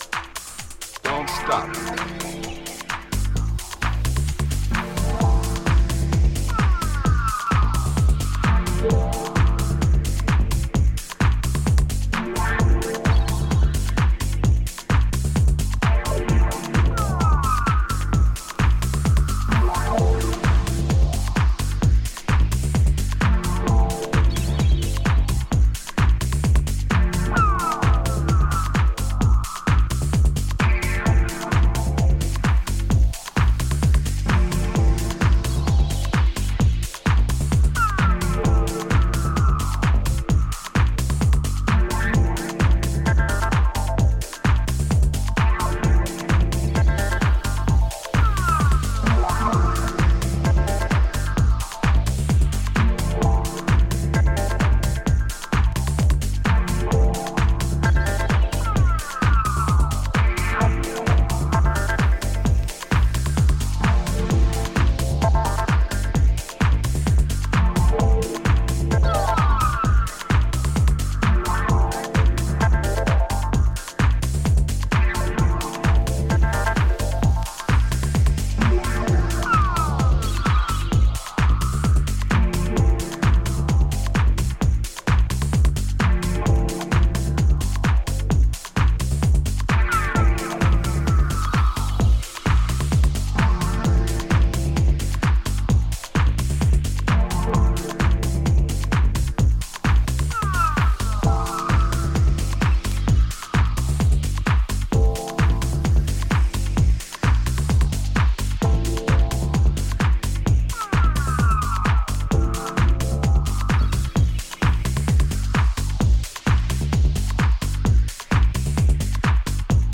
燻んだボトムとジャズキーをアブストラクトに、それでいて美しく響せた